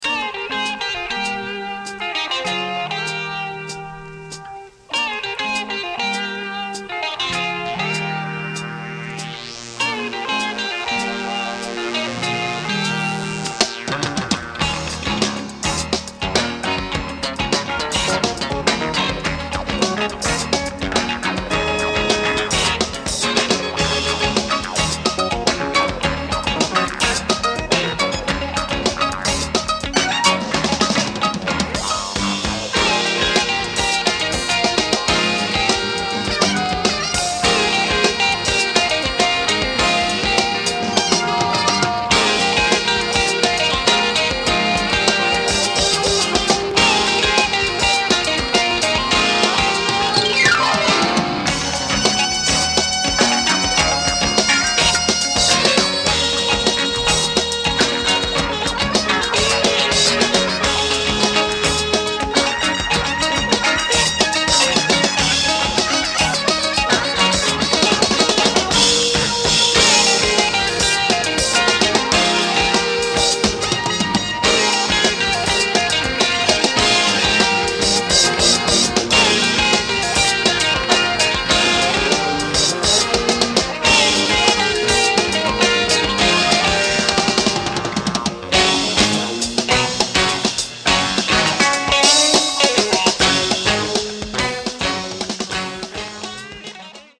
Killer jazz-funk